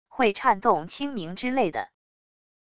会颤动轻鸣之类的wav音频